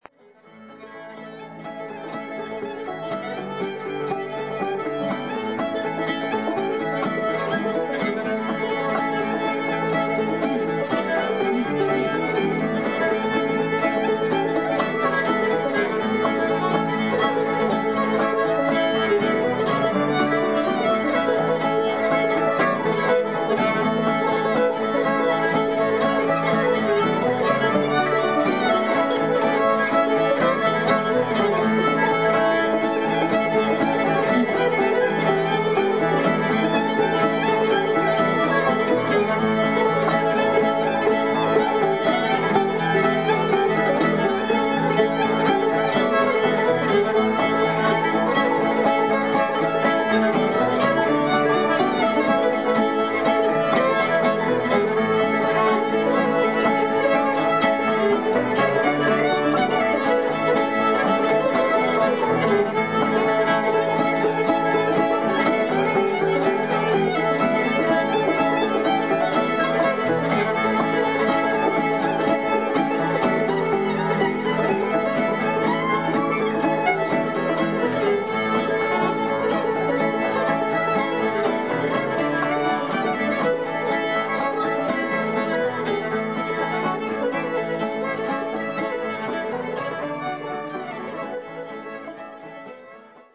Fiddle
Banjo